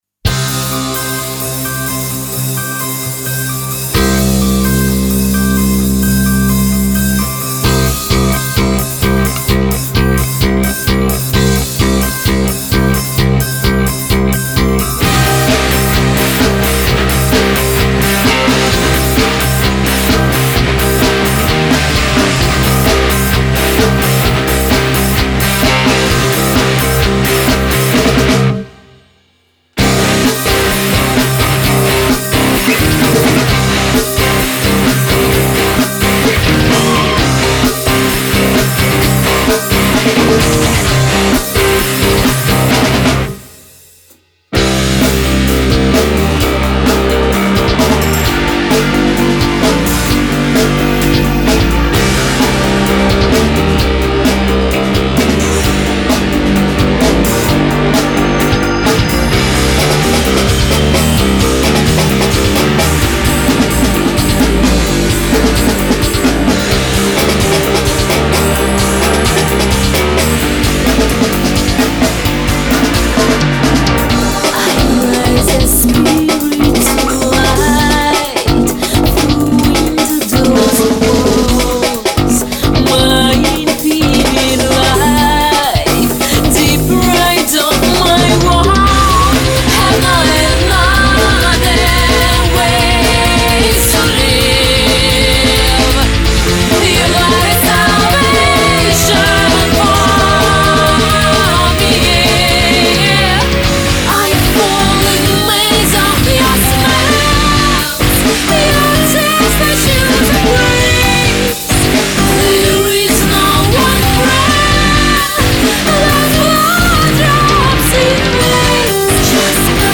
вокал
клавиши
бас, вокал
гитара
ударные